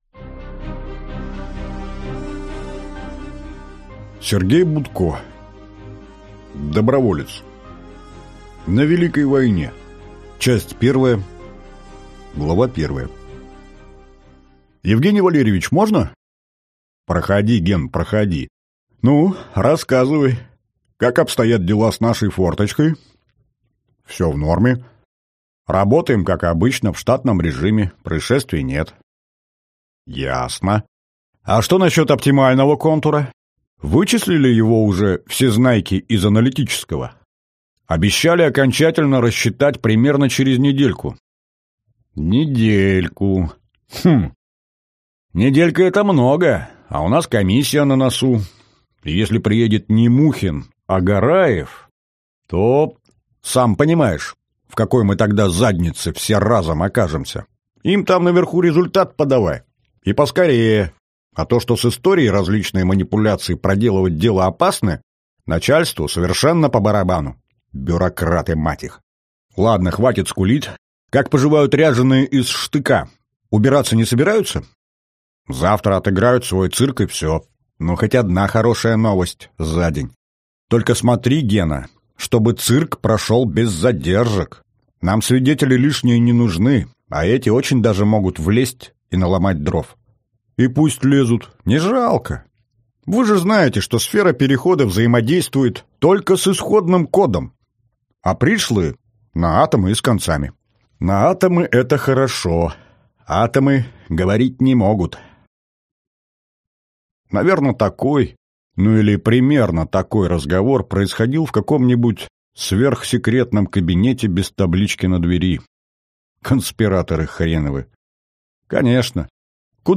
Аудиокнига Доброволец. На Великой войне | Библиотека аудиокниг